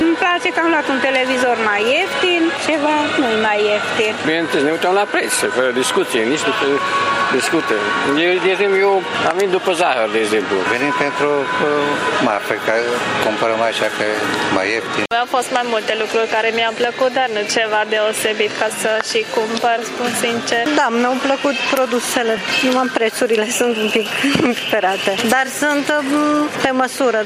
Tîrgumureșenii spun că, de multe ori, reducerile vizează produse mai puțin interesante, iar prețurile sunt tot mari.